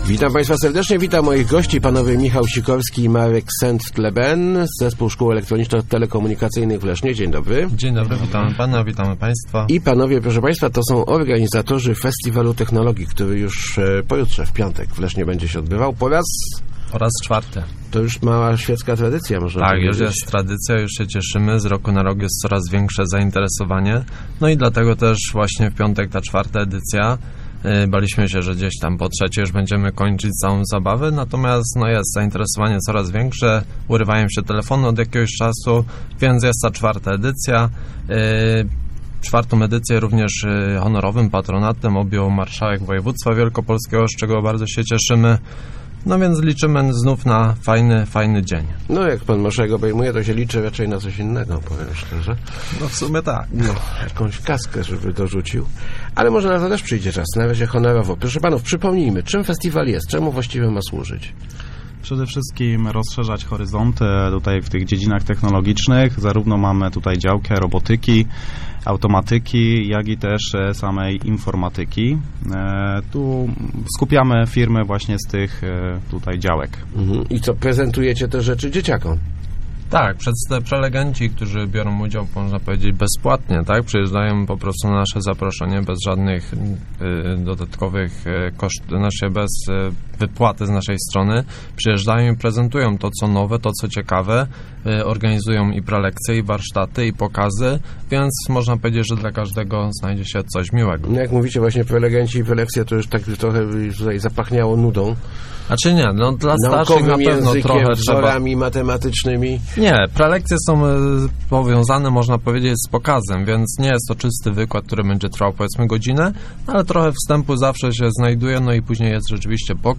Start arrow Rozmowy Elki arrow Festiwal Technologii